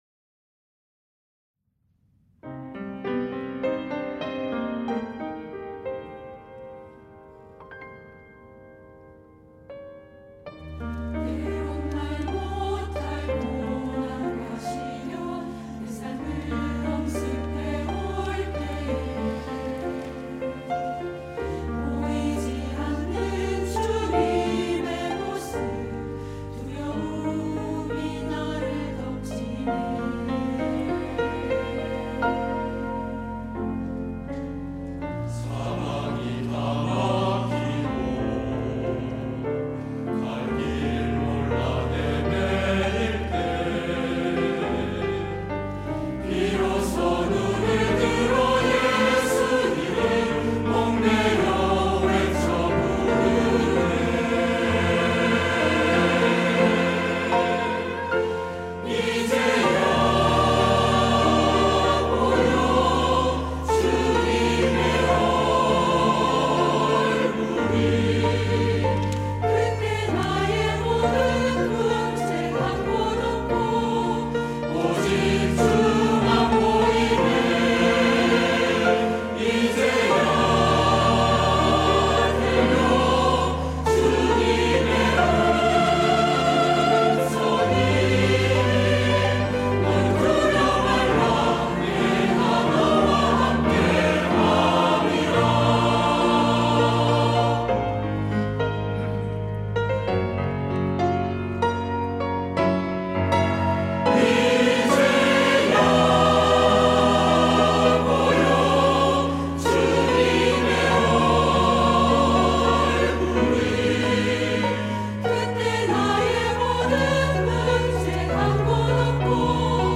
할렐루야(주일2부) - 이제야 보이네
찬양대